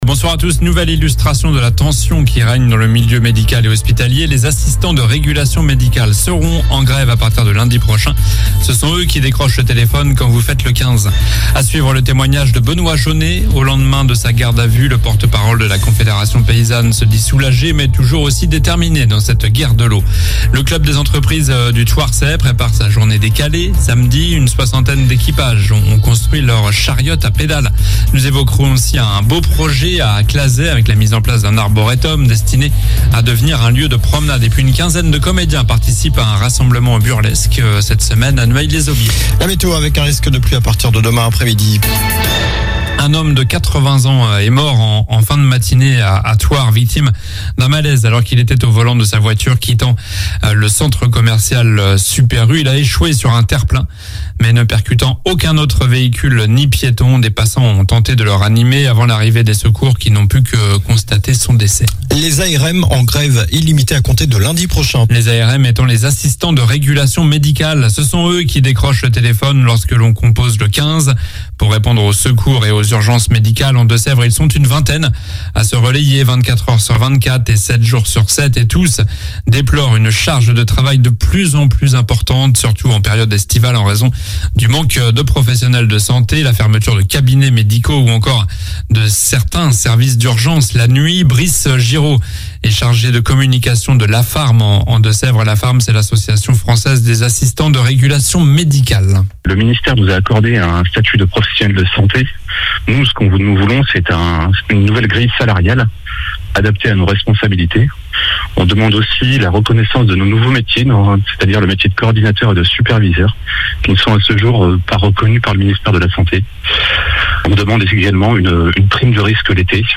Journal du jeudi 29 juin (soir)